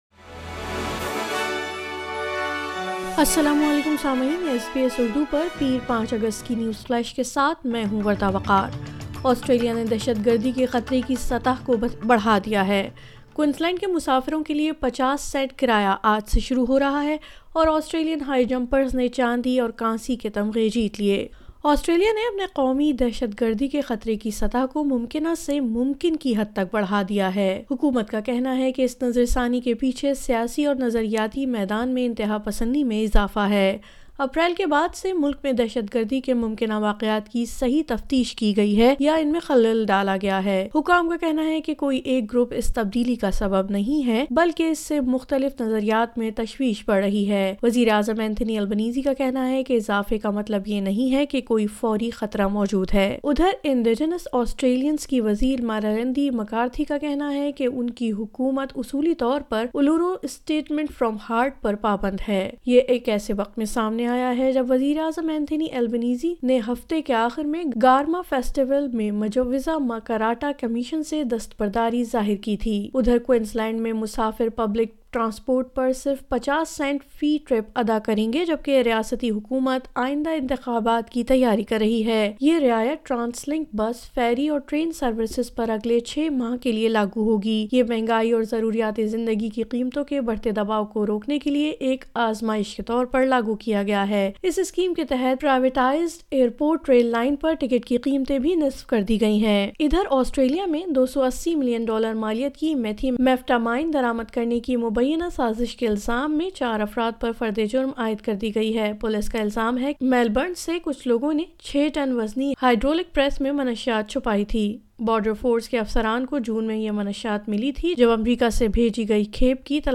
مزید خبروں کے لئے سنئے اردو نیوز فلیش